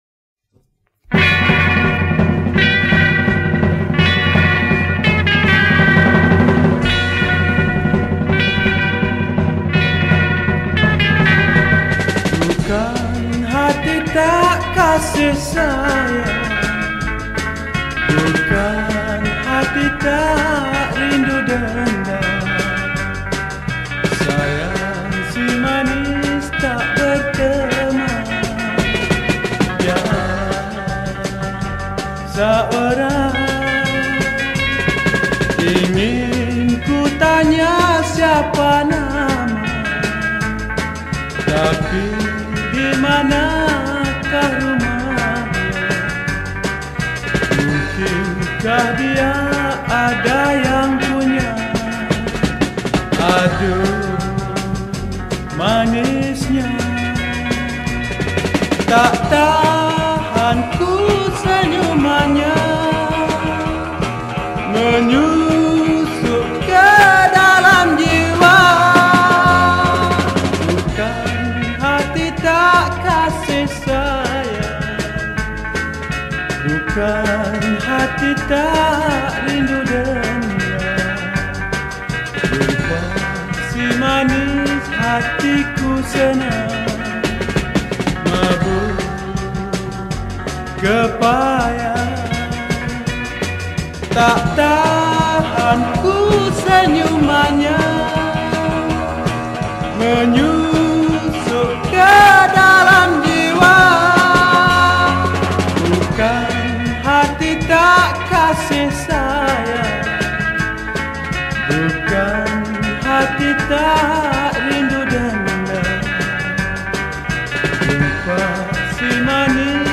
Pop Yeh Yeh